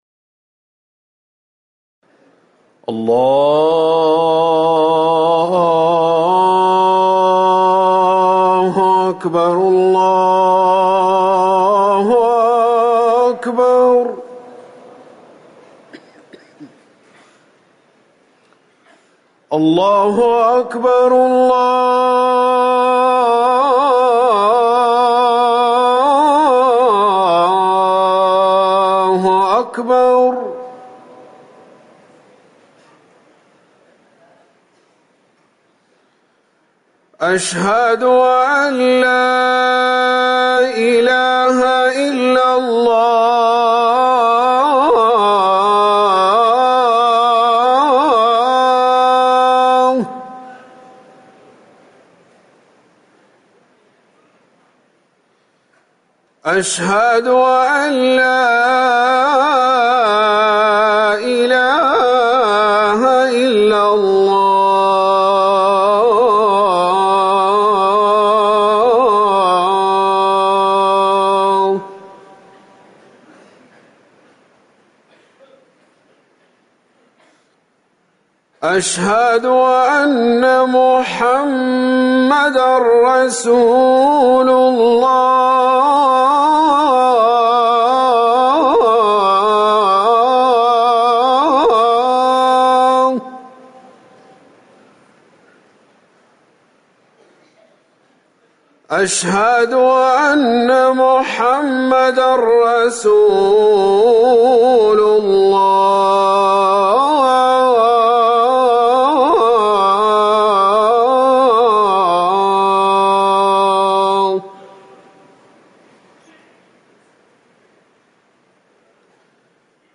أذان المغرب
تاريخ النشر ١٢ محرم ١٤٤١ هـ المكان: المسجد النبوي الشيخ